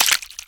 用鱼攻击音效